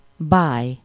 Ääntäminen
IPA: [ʃaʁm]